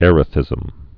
(ĕrə-thĭzəm)